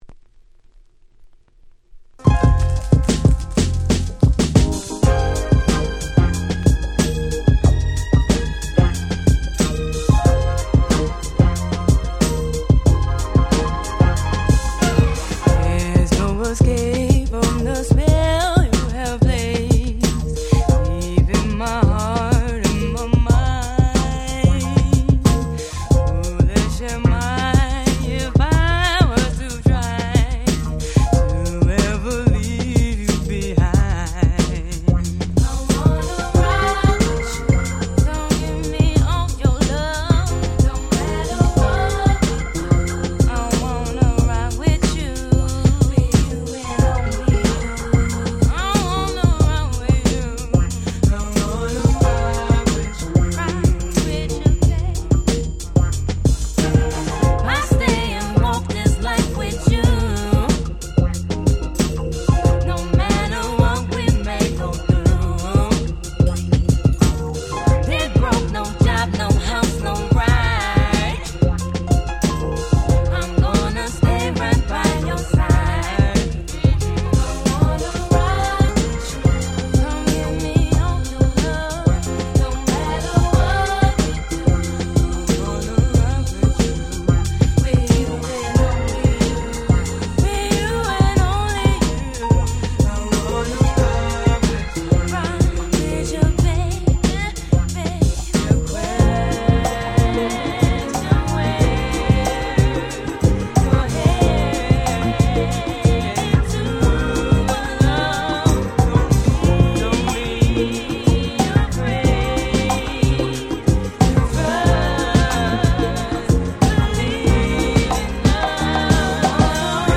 01' Nice R&B !!
Coolでめちゃ格好良い1曲でアルバムの中でも特に人気だった1曲。